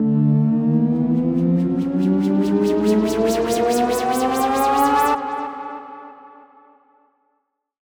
SOUTHSIDE_fx_cheesy_ufo.wav